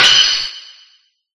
Sword2.ogg